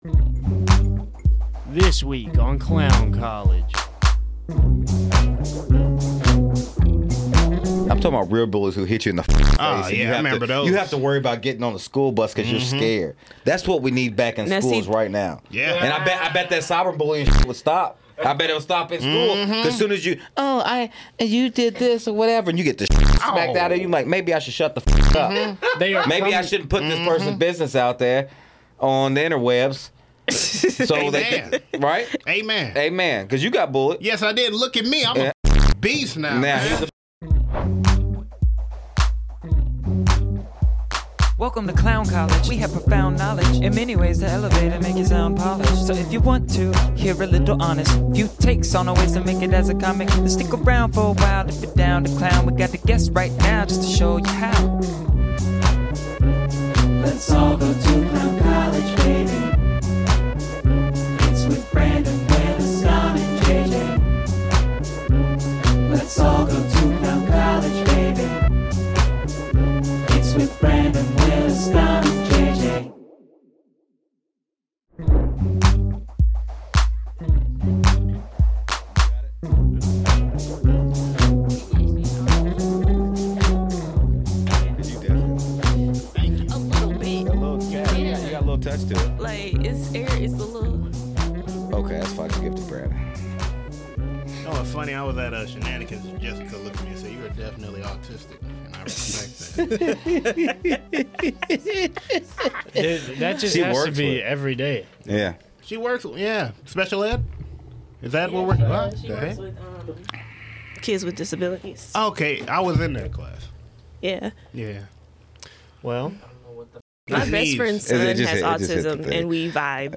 on the 1's and 2's. Get ready to hear about comedy in a way I guarantee you never have before. Reactions, interviews, skits, you name it.